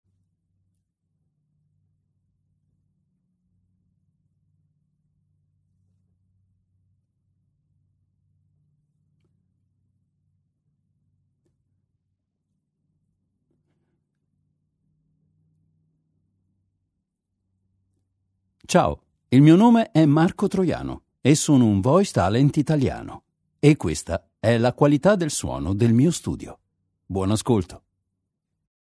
Male
Authoritative, Character, Confident, Cool, Deep, Warm, Versatile
Neutral Italian with perfect pronunciation and Italian dialect inflections.
Microphone: AKG C414 XL 2 - Rhode NT2 vintage